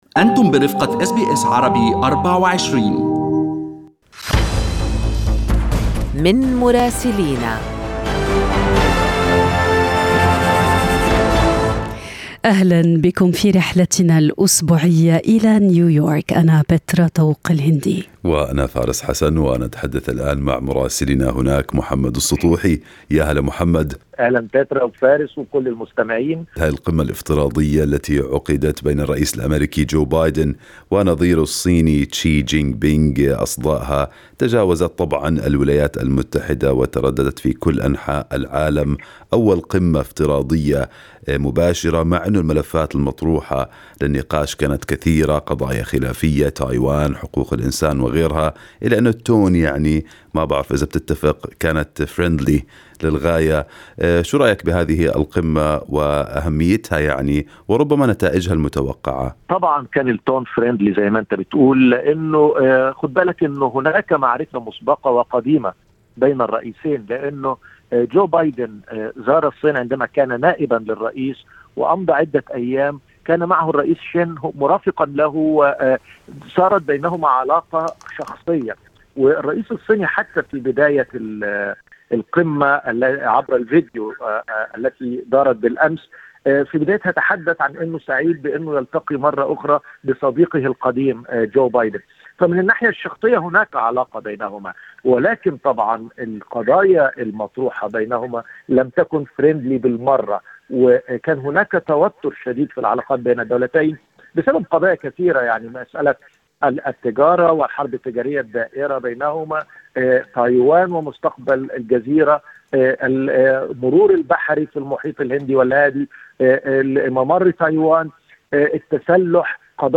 من مراسلينا: أخبار الولايات المتحدة الأمريكية في أسبوع 18/11/2021